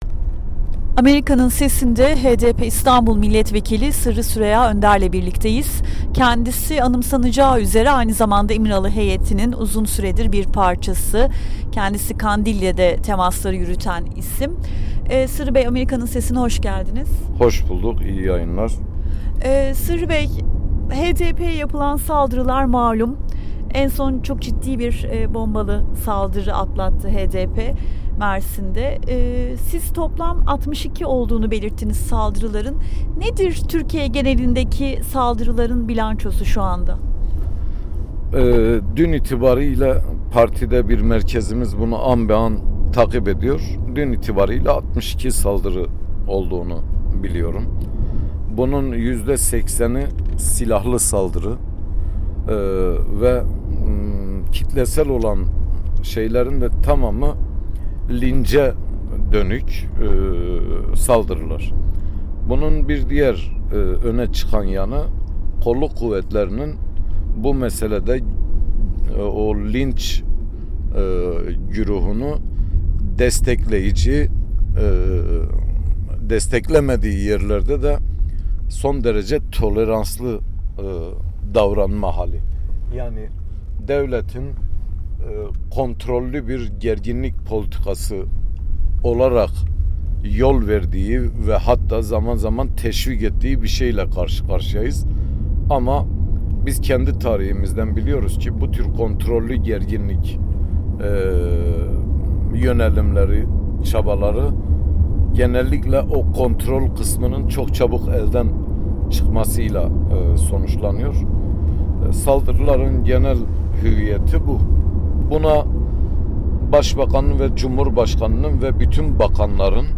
Sırrı Süreyya Önder'le söyleşi